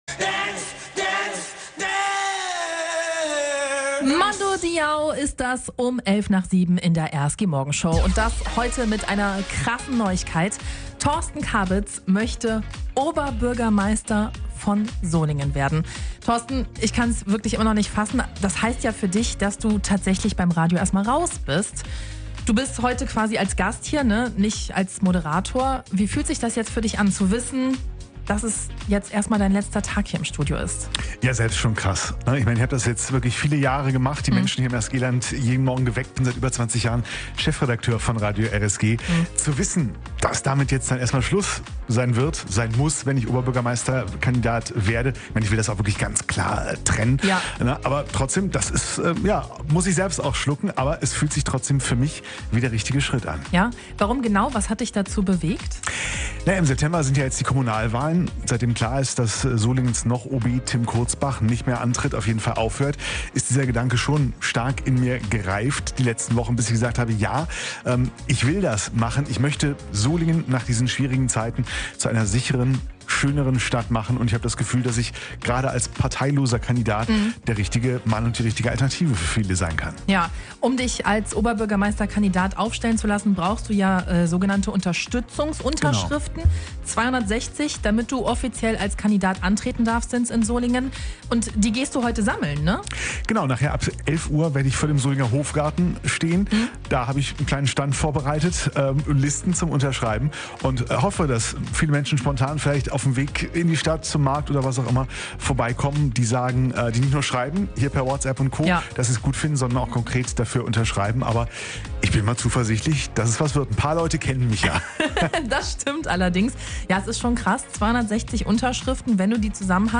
Und so klang die Verkündung live in der RSG-Morgenshow: